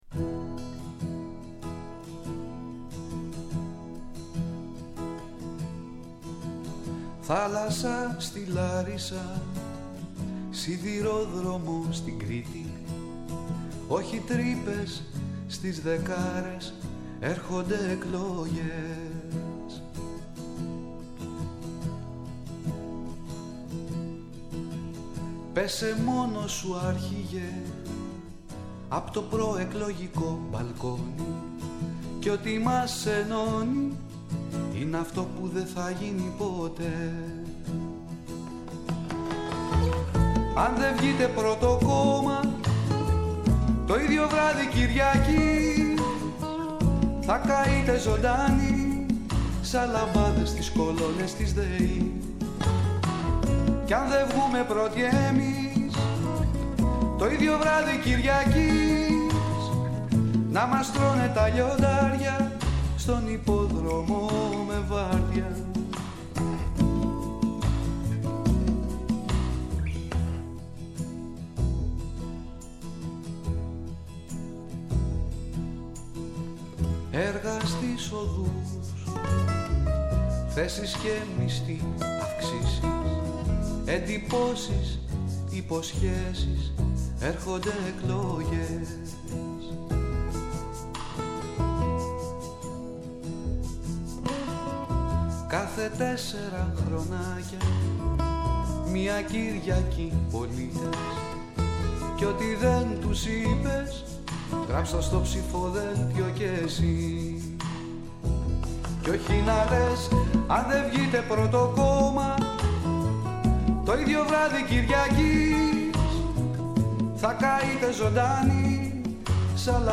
Καλεσμένοι τηλεφωνικά στην σημερινή εκπομπή: